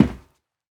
StepMetal6.ogg